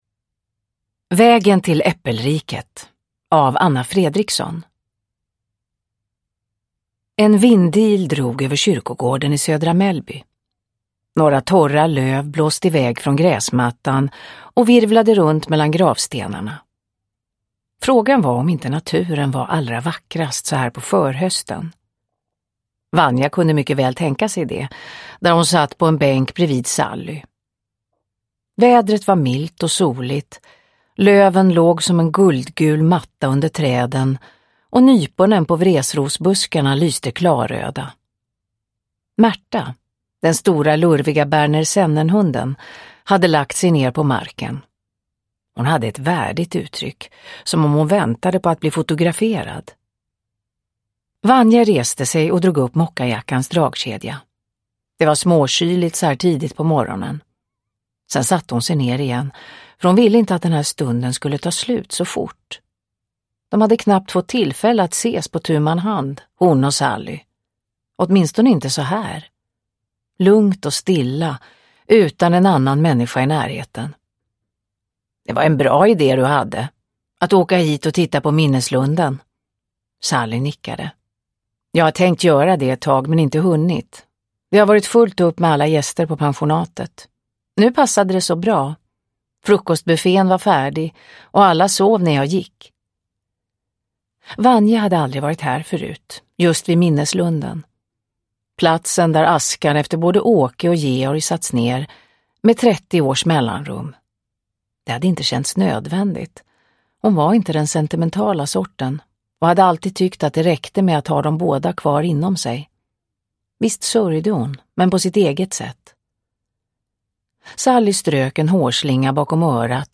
Vägen till äppelriket – Ljudbok – Laddas ner
Uppläsare: Marie Richardson